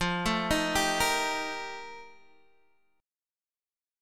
F11 chord {x 8 7 8 6 6} chord